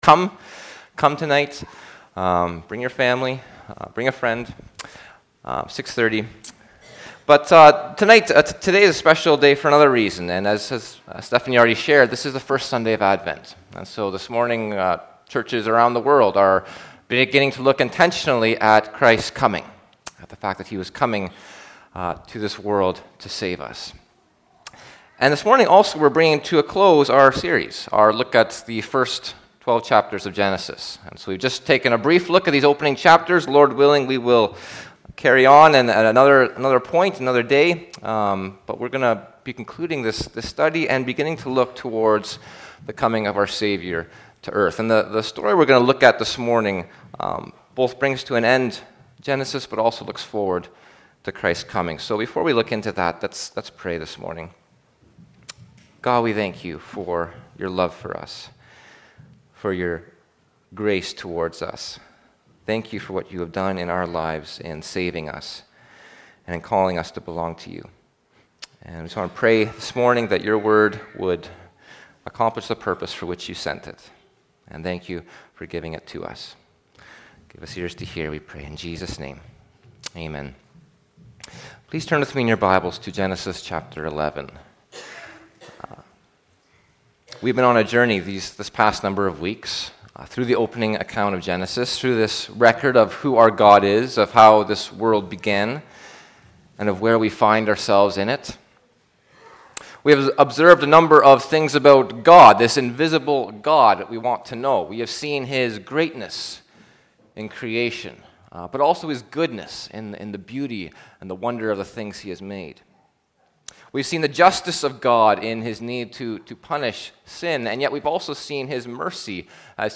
Posted in Sermons .